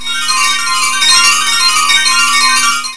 Alarm bells